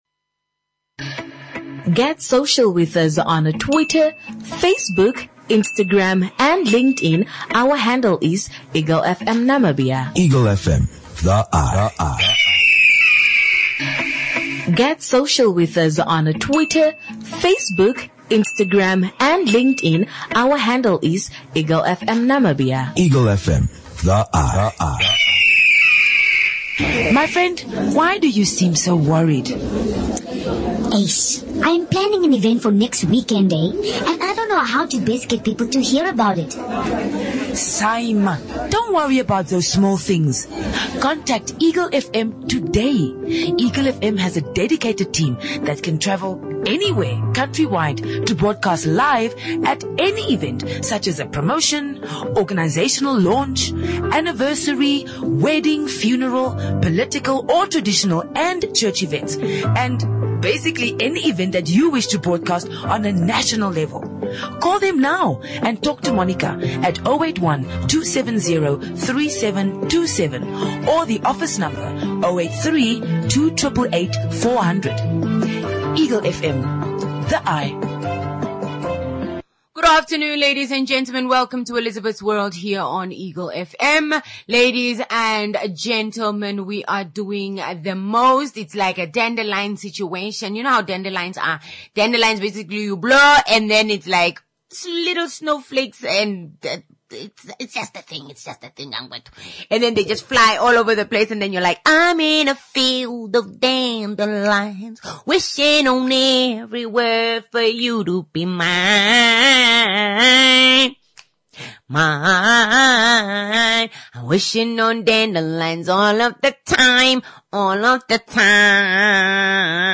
WHERE ARE PLAYING MUSIC.